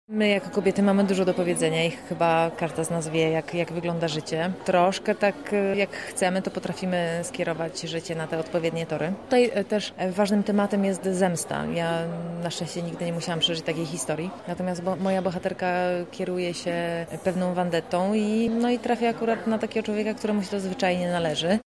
– W tym filmie kobiety są górą – mówi Olga Bołądź, aktorka grająca Wiki: